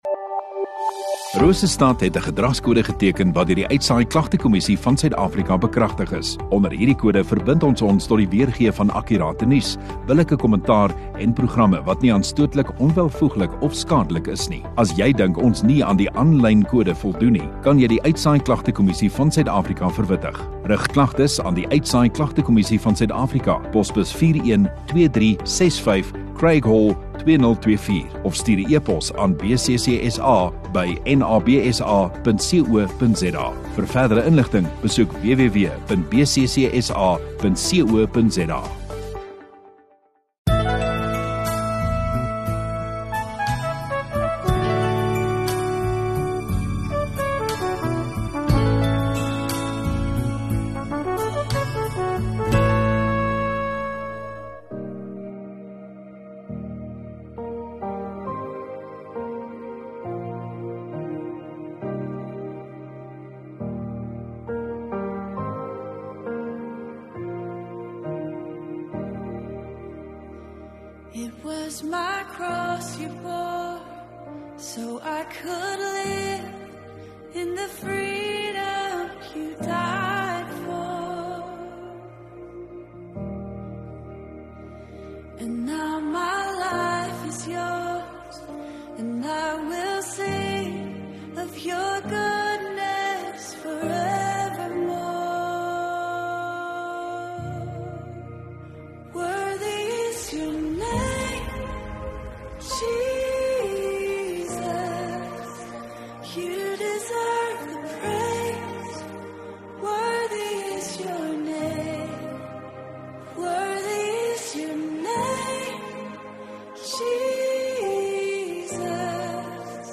12 Apr Saterdag Oggenddiens